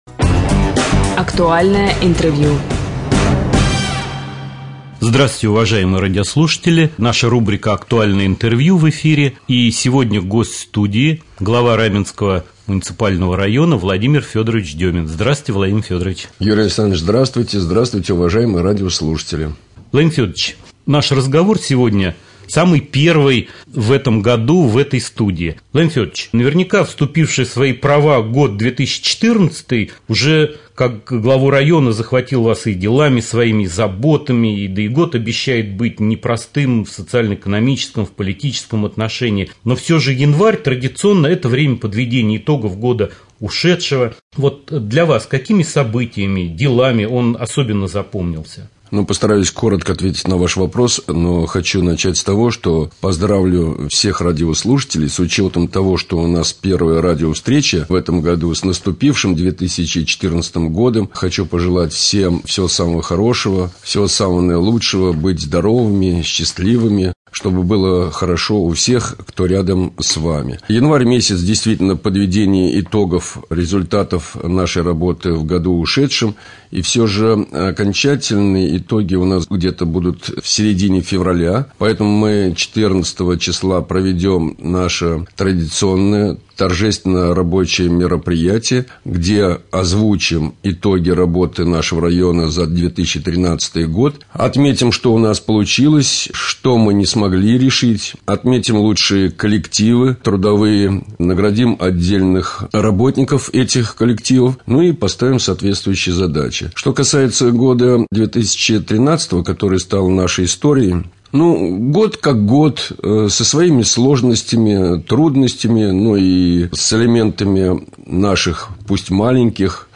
В студии Демин Владимир Фёдорович
« Актуальное интервью». В гостях у Раменского радио глава Раменского района В.Ф.Демин.